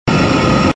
hum_tl_pulse.wav